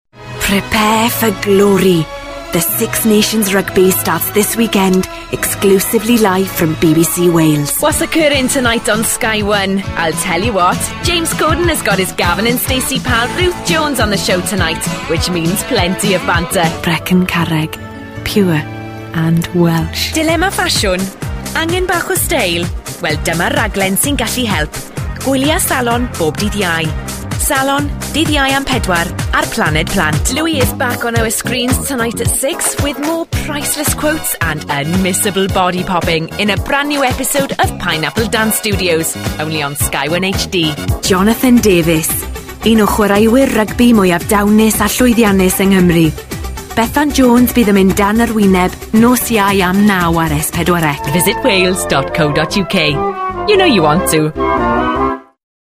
• Female
• Standard English R P
• Welsh
Showing: Promos & Idents Clips